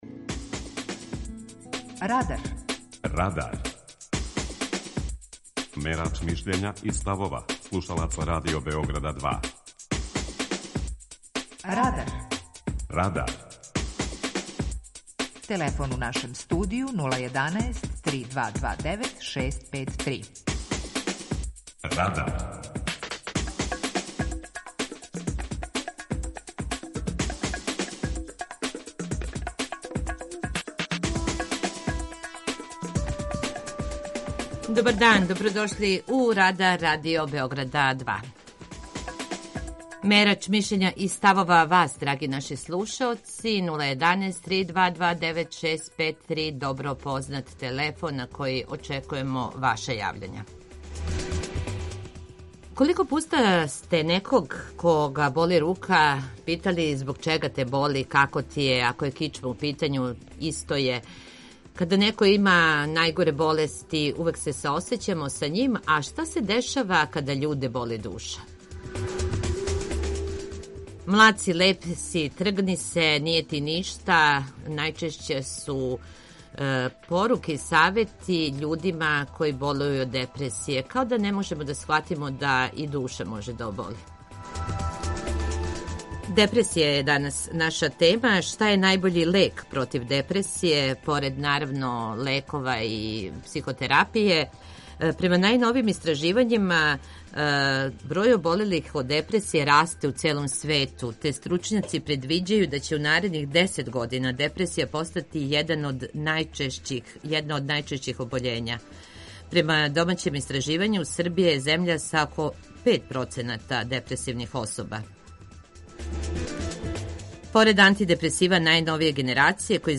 Питање Радара је: Шта је најбољи „лек" против депресије? преузми : 18.67 MB Радар Autor: Група аутора У емисији „Радар", гости и слушаоци разговарају о актуелним темама из друштвеног и културног живота.